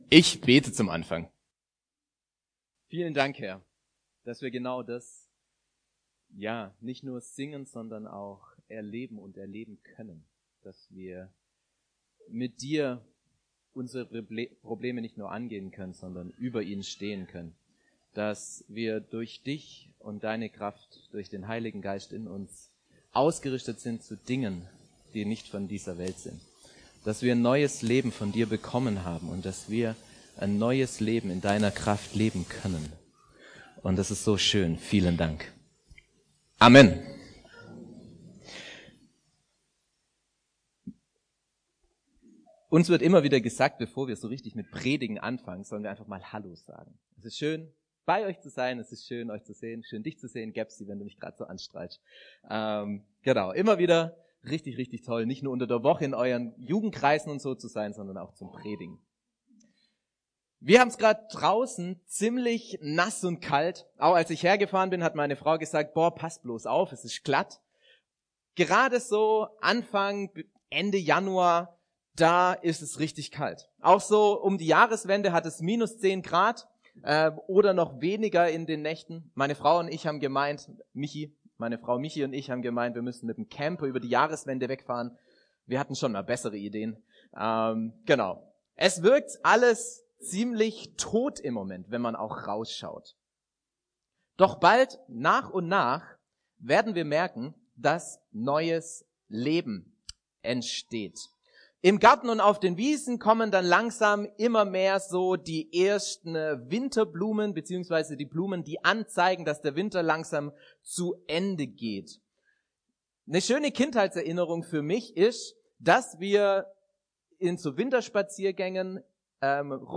Neues Leben - neu gelebt. - Eph 4,17 - 5,2 ~ Predigten aus der Fuggi